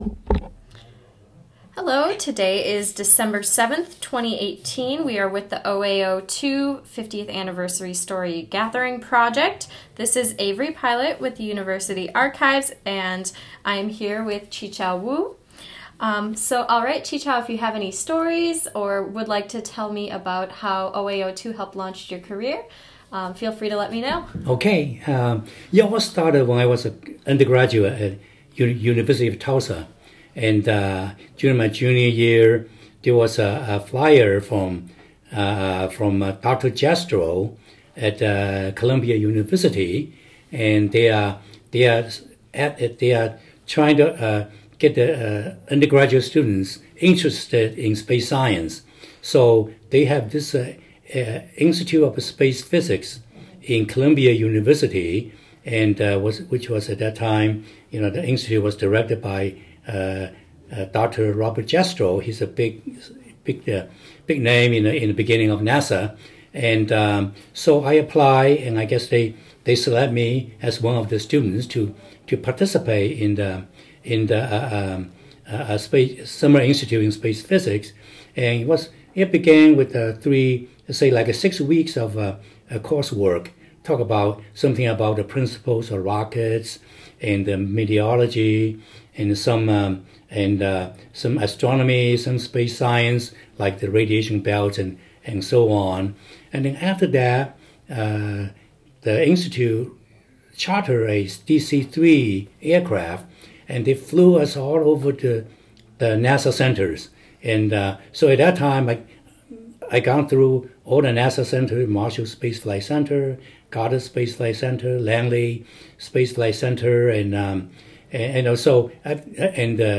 University of Wisconsin-Madison Oral History Program